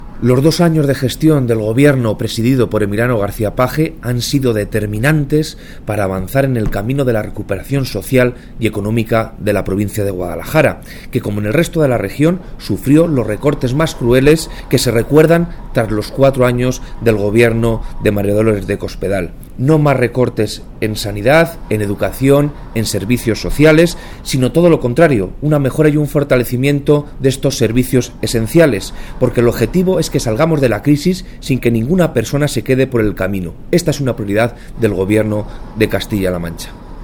El delegado de la Junta en Guadalajara, Alberto Rojo, habla de los avances experimentados en la provincia en los dos primeros años de Gobierno de Emiliano García-Page.